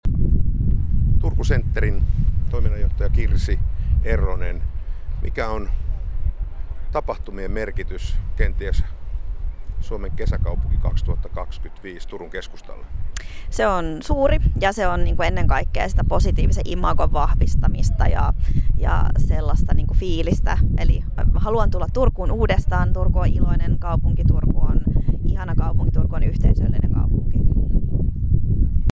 Paikka oli Forum korttelin keskusaula.